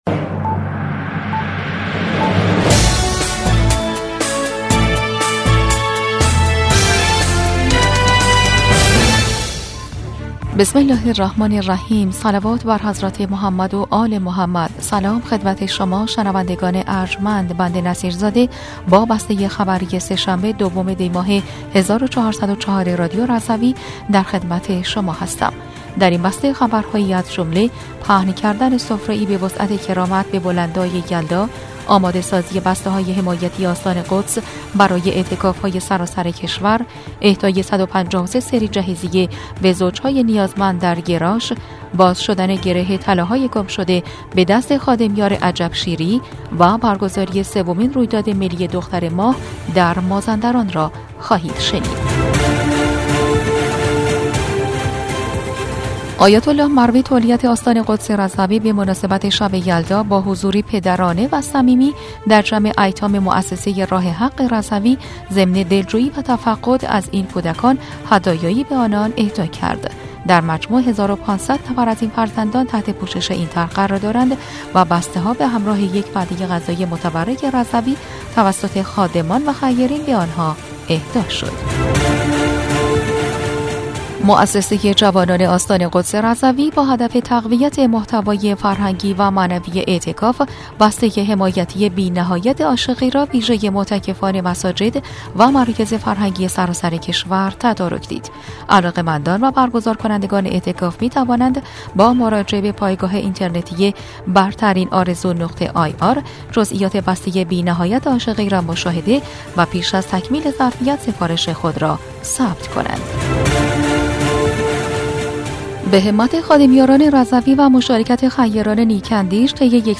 بسته خبری ۲ دی ۱۴۰۴ رادیو رضوی؛